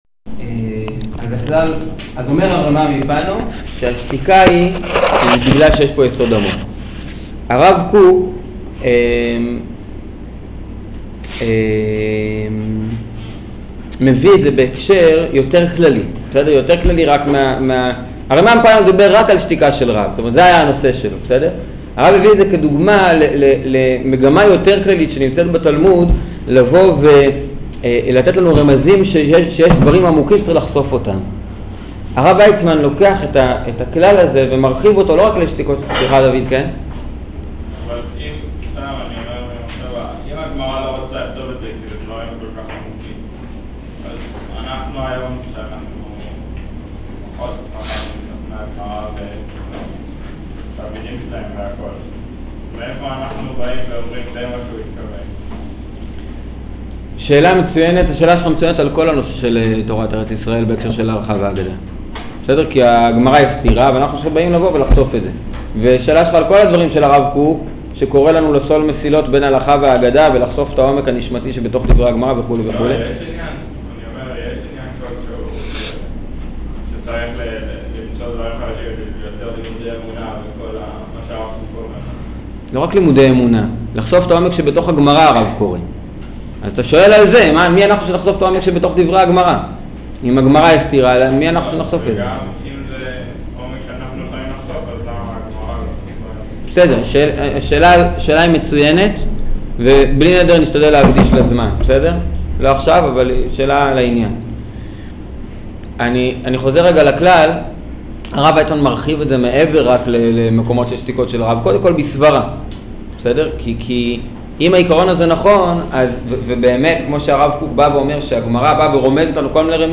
שיעור גאולה ותשובה